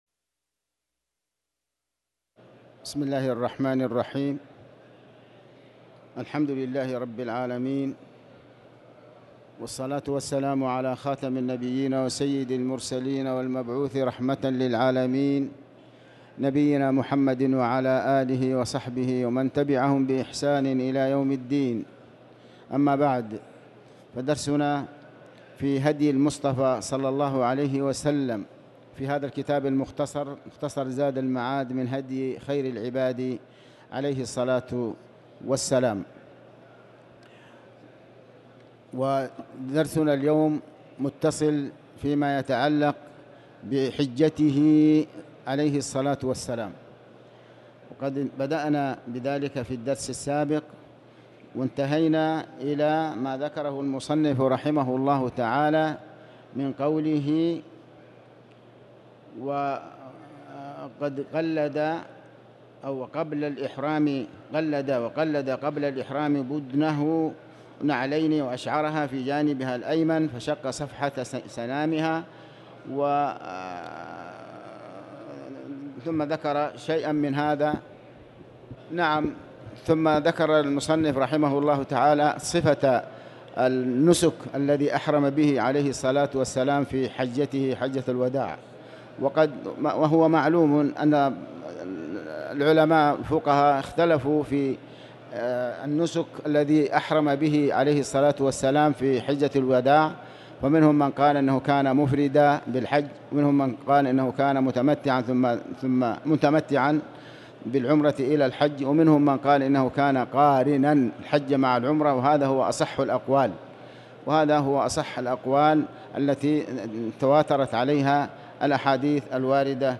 تاريخ النشر ٢١ ذو القعدة ١٤٤٠ هـ المكان: المسجد الحرام الشيخ: علي بن عباس الحكمي علي بن عباس الحكمي مناسك الحج